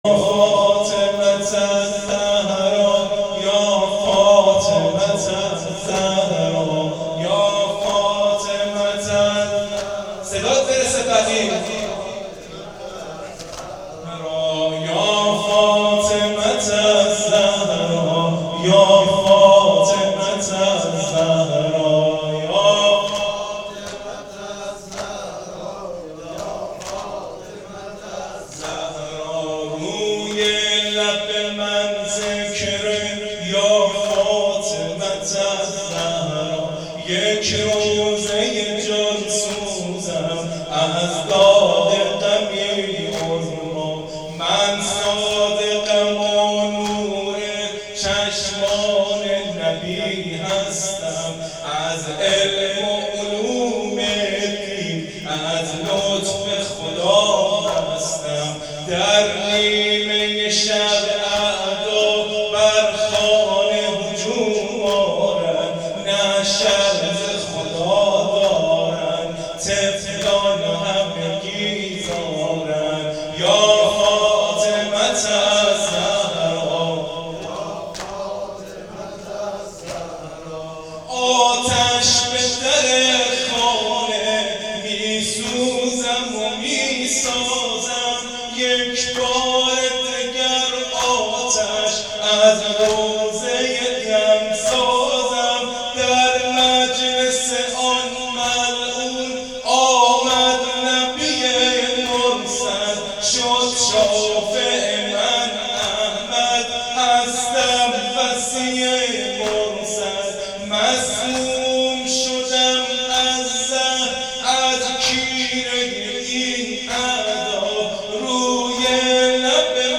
نوحه یا فاطمه الزهرا ، شهادت حضرت امام جعفر صادق علیه السلام